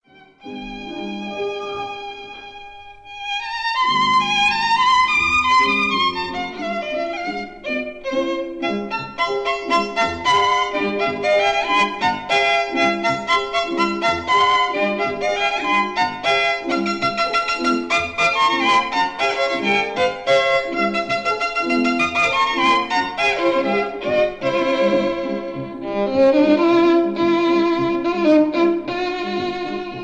No. 8 in C major
violin
piano